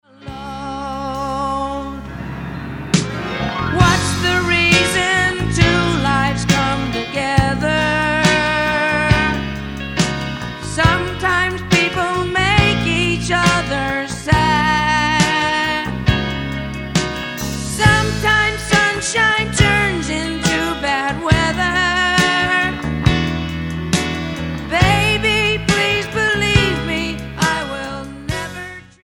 STYLE: Jesus Music